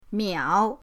miao3.mp3